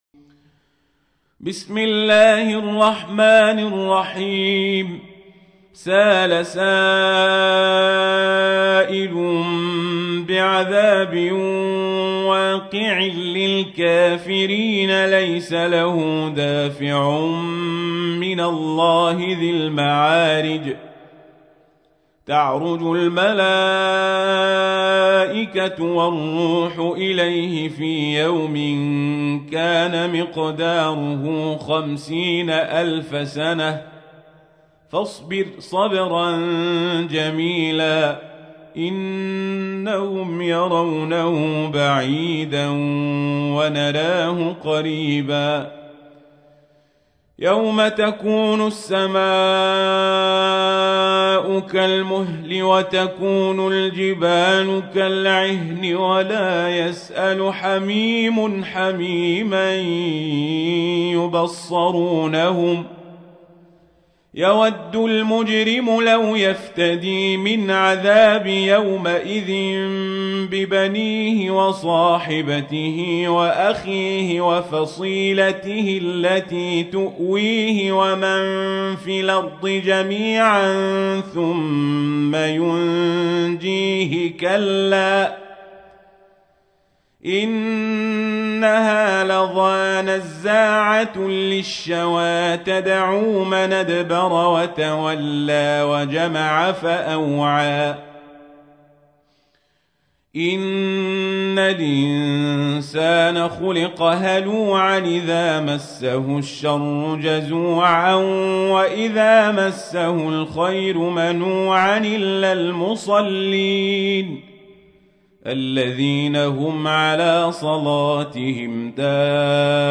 تحميل : 70. سورة المعارج / القارئ القزابري / القرآن الكريم / موقع يا حسين